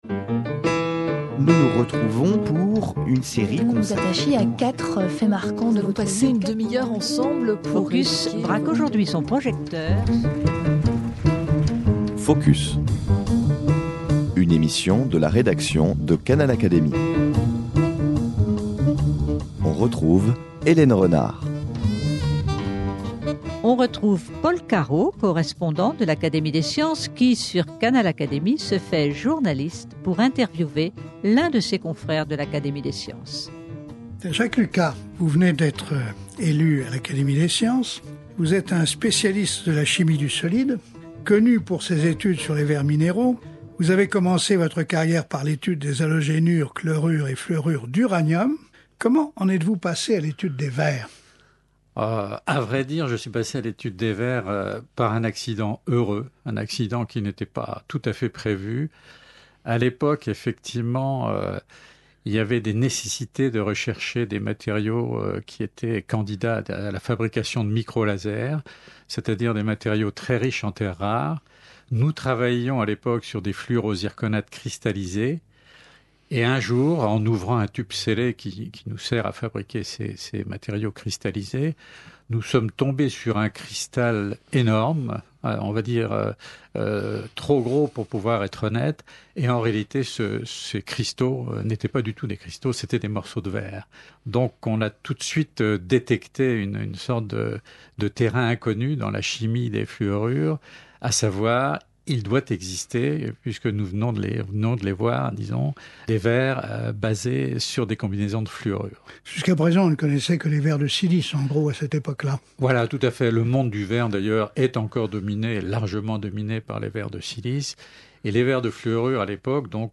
Ses recherches sont aujourd’hui mondialement utilisées dans le domaine des télécommunications. Interview